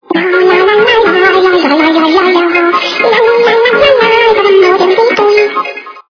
- звуки для СМС
При прослушивании Щасливая девочка - поет радостную песню при получении СМС качество понижено и присутствуют гудки.
Звук Щасливая девочка - поет радостную песню при получении СМС